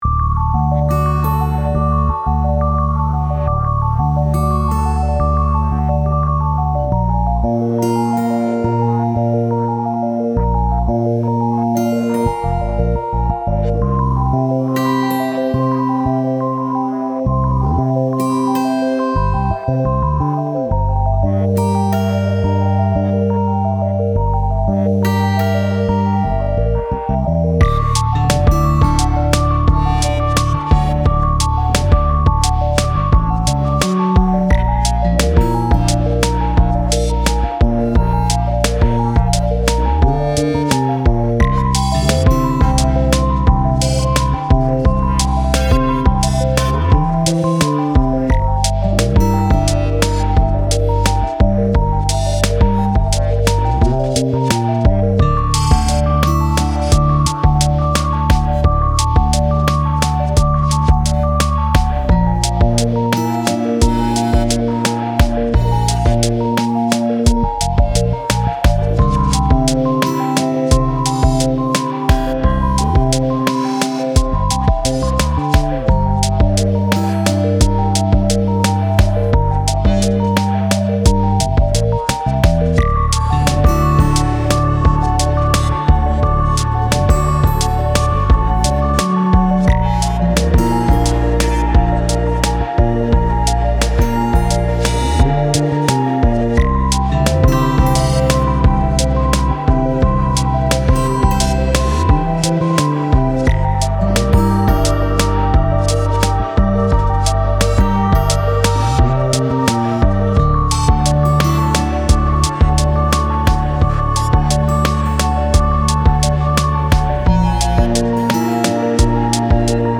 Style Style Ambient, EDM/Electronic
Mood Mood Calming, Mysterious, Relaxed
Featured Featured Acoustic Guitar, Bass, Choir +3 more
BPM BPM 87